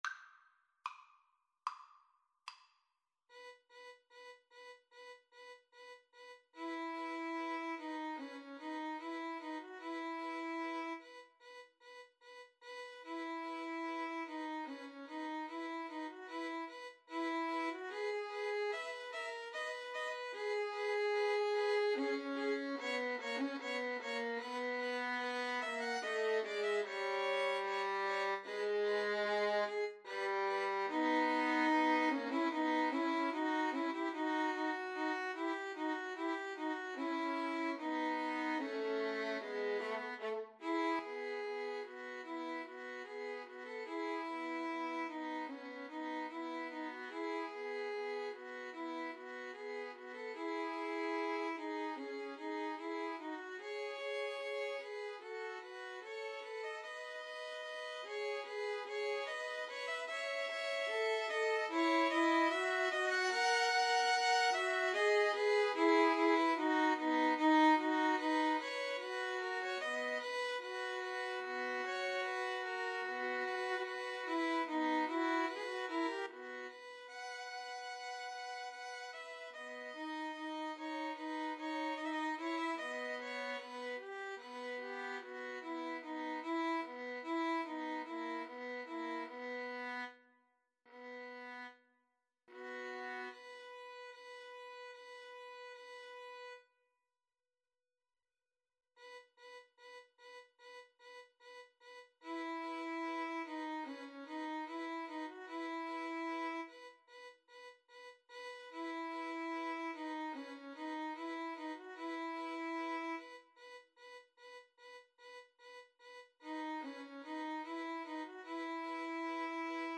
Free Sheet music for Violin Trio
4/4 (View more 4/4 Music)
~ = 74 Moderato
E major (Sounding Pitch) (View more E major Music for Violin Trio )